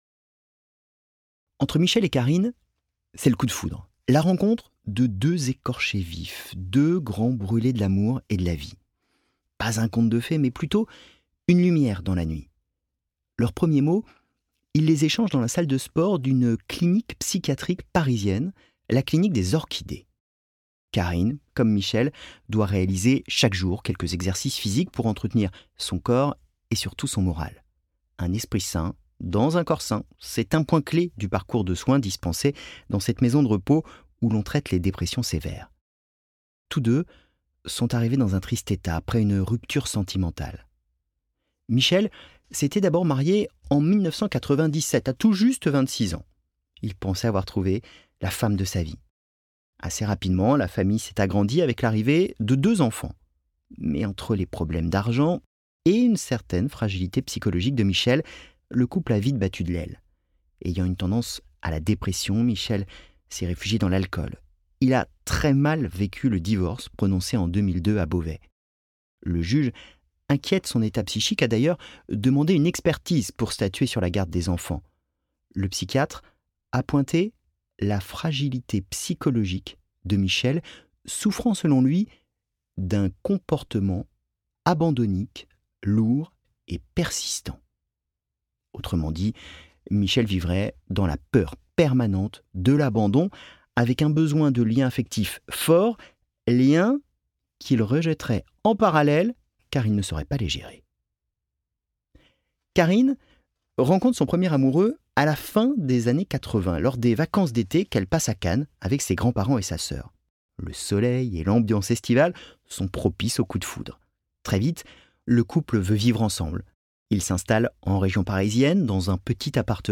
De l'affaire Daval aux serial killers les plus insaisissables, ce livre audio décortique les mécanismes du mensonge criminel. Comment ces individus dupent-ils leur entourage, les médias, parfois la France entière ?